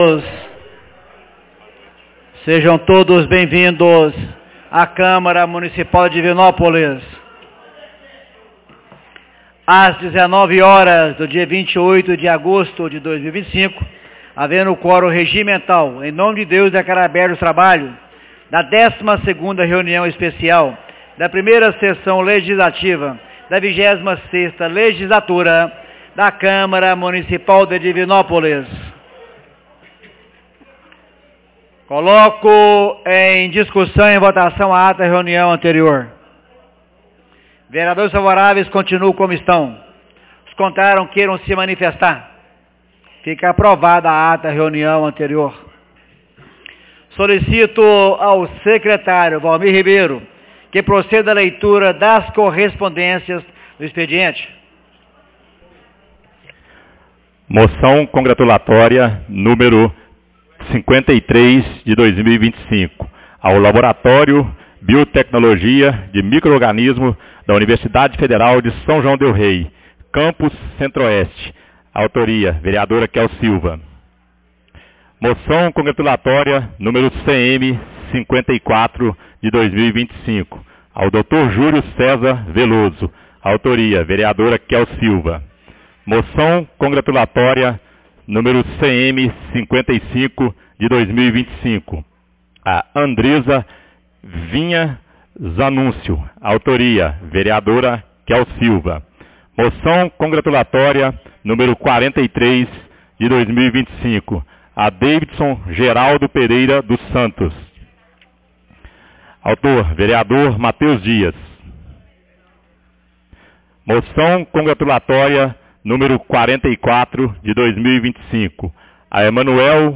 12ª Entrega de Moçoes 28 de agosto de 2025